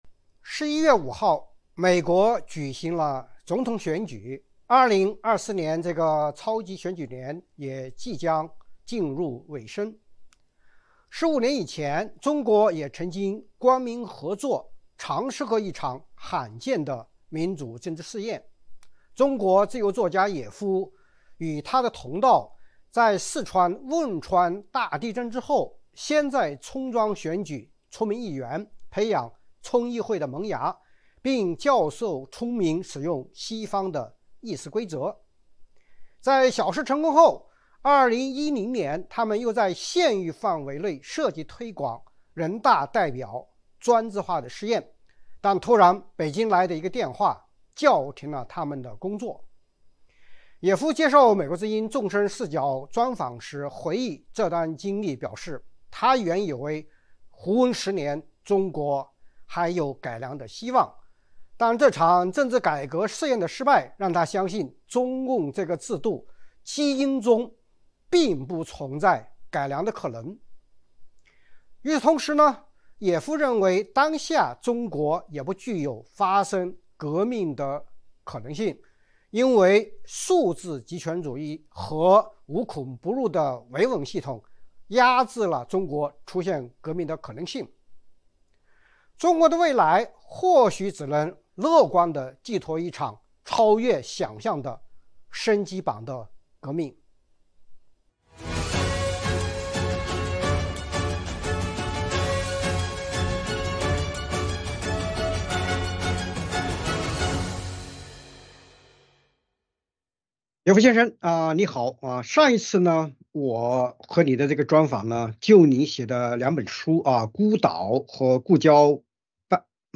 专访野夫：一场低调政改实验的夭折和中国改良梦的终结
《纵深视角》节目进行一系列人物专访，受访者发表的评论不代表美国之音的立场 。